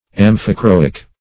Search Result for " amphichroic" : The Collaborative International Dictionary of English v.0.48: Amphichroic \Am`phi*chro"ic\, a. [Gr.